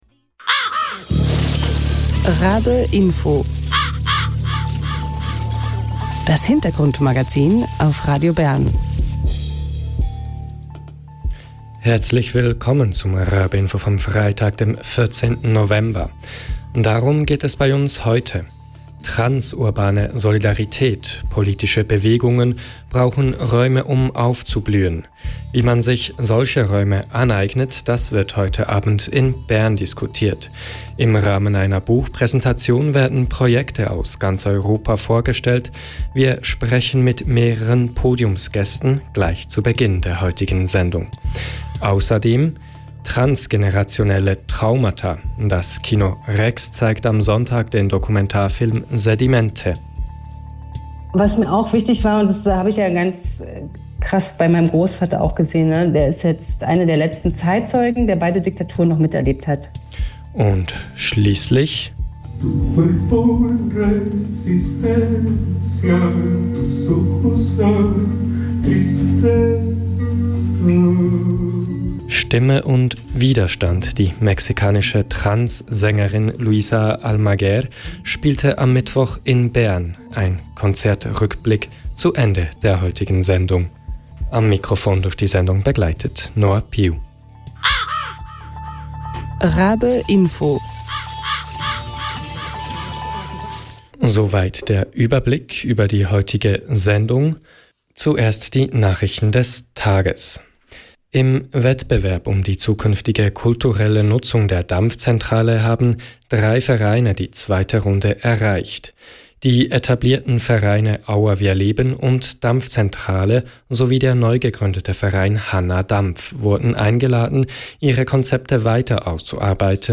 Gleich zu Beginn der heutigen Sendung sprechen wir mit mehreren Podiumsgästen darüber....